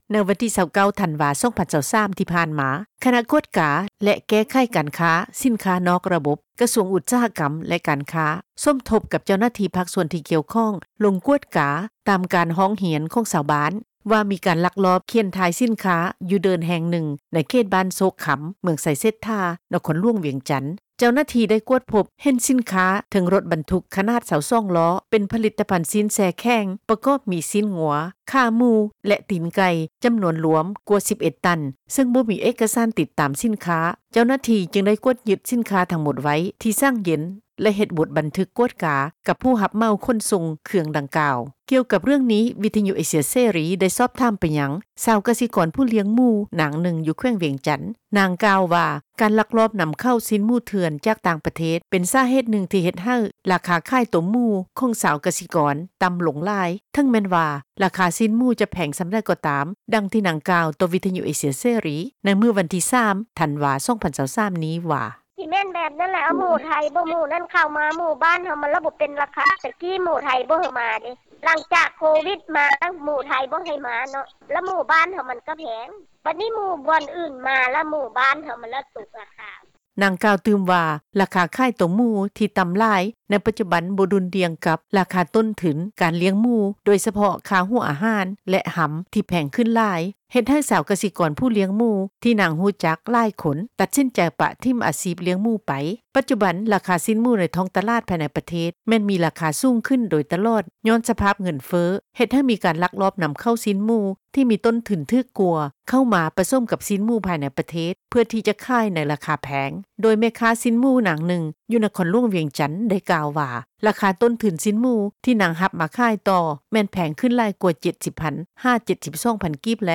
ກ່ຽວກັບເຣື່ອງນີ້, ວິທຍຸເອເຊັຽເສຣີ ໄດ້ສອບຖາມ ຊາວກະສິກອນ ຜູ້ລ້ຽງໝູ ນາງນຶ່ງ ຢູ່ແຂວງວຽງຈັນ. ນາງ ກ່າວວ່າ ການລັກລອບນຳເຂົ້າ ຊີ້ນໝູເຖື່ອນ ຈາກຕ່າງປະເທດ ເປັນສາເຫດນຶ່ງທີ່ເຮັດໃຫ້ ລາຄາຂາຍໝູ ຂອງຊາວກະສິກອນ ຕ່ຳລົງຫຼາຍ.